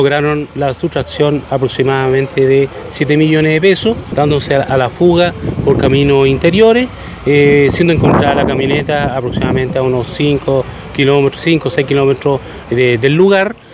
Así lo confirmó el prefecto de Carabineros de Bío Bío, el coronel Hugo Zenteno.